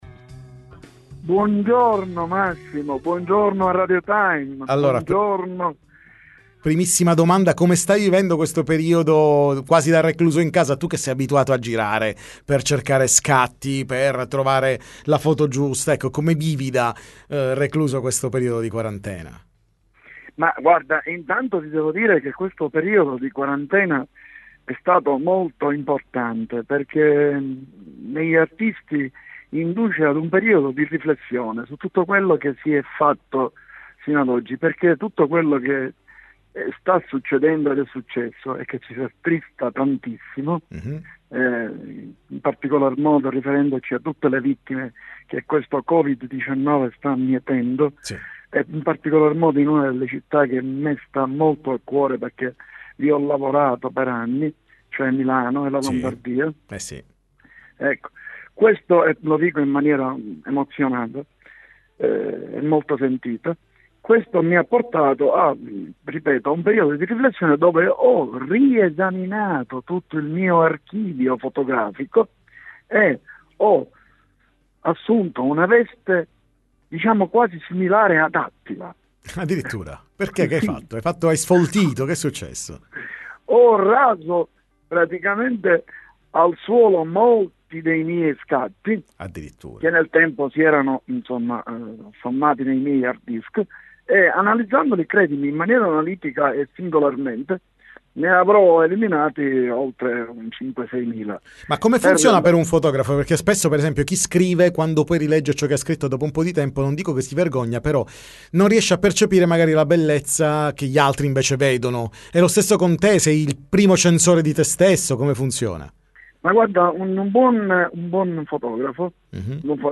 Time Magazine intervista